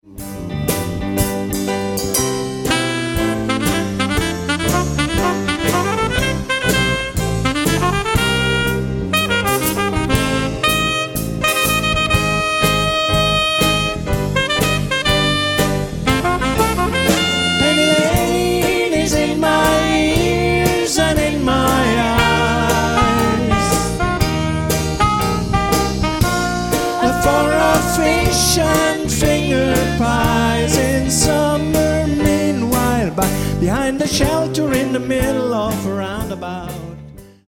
Jubiläumskonzert